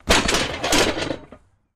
Football Sled Hits; Single Players 2x, Close Perspective